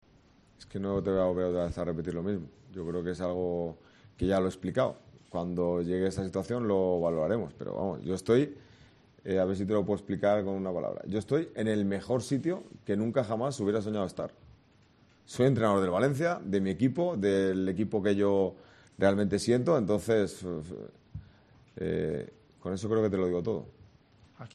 El entrenador del Valencia, Rubén Baraja, dijo en la rueda de prensa previa al partido contra el Sevilla de este viernes, que, si pudiera hablar con el máximo accionista del club, Peter Lim, le trasladaría que el equipo necesita refuerzos y agregó que no hay que normalizar esta situación.